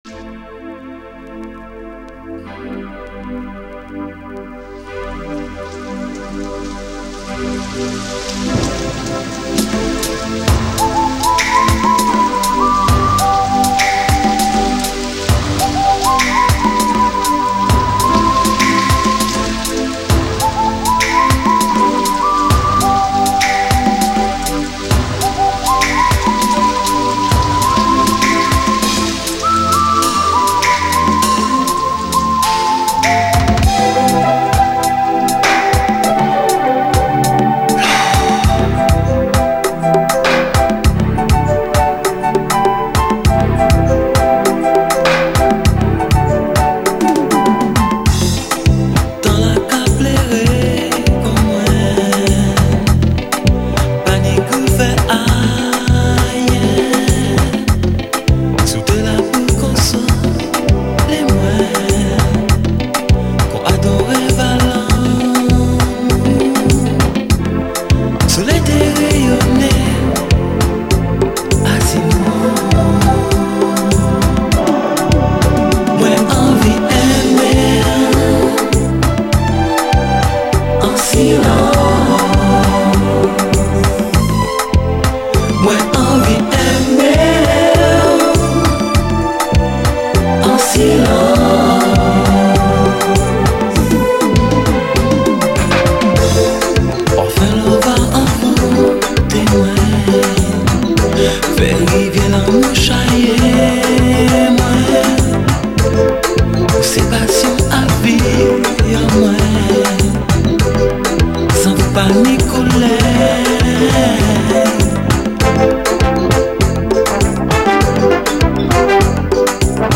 CARIBBEAN
メロウで甘いズーク=”ZOUK LOVE”というサブ・ジャンルの存在！
雨のSEもロマンティックな傑作ズーク・ラブ